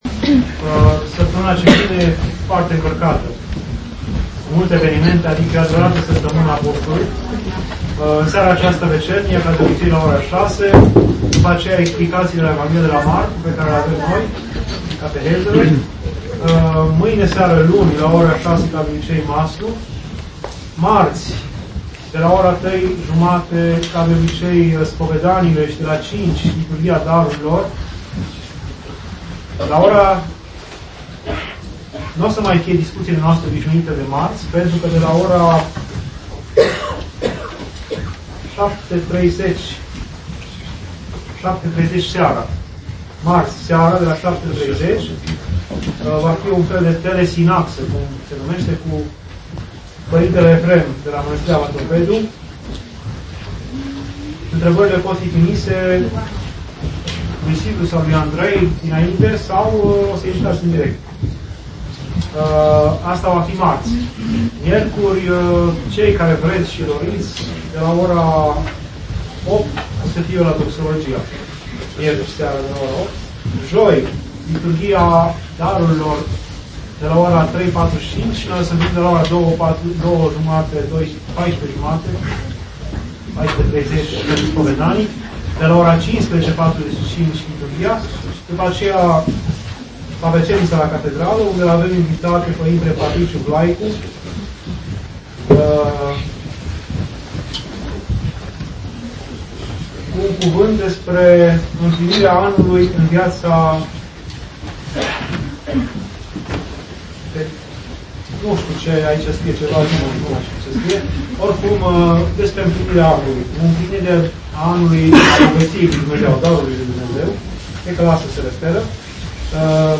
Anunțuri